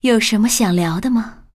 文件 文件历史 文件用途 全域文件用途 Dana_tk_01.ogg （Ogg Vorbis声音文件，长度1.5秒，109 kbps，文件大小：20 KB） 源地址:游戏语音 文件历史 点击某个日期/时间查看对应时刻的文件。